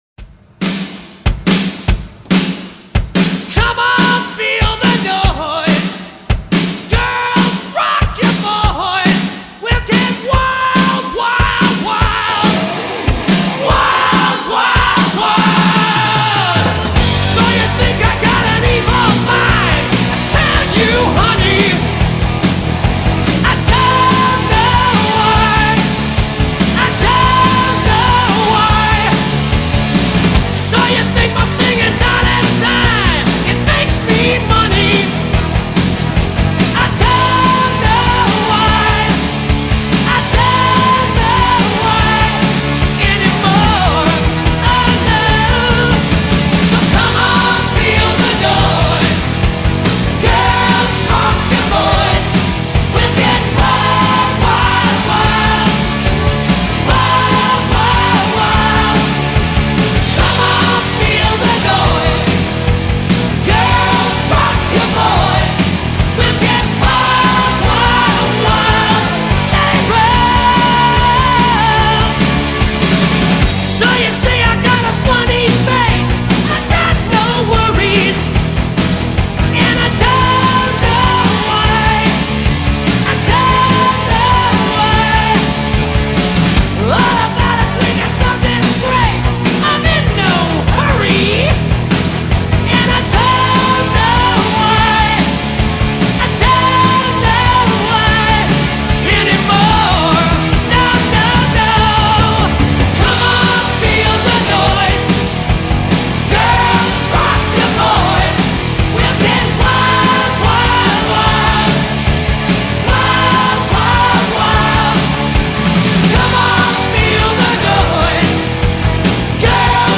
Gênero: Rock